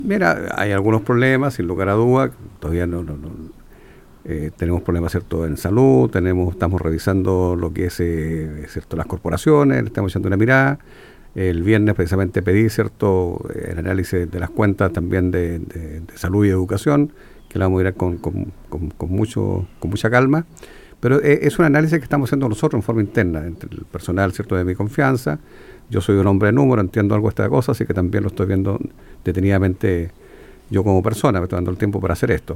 En conversación con Radio Bío Bío, Bertín expresó que “siempre hay dificultades, siempre hay cosas que ordenar, siempre hay que tomar decisiones para ir avanzando”.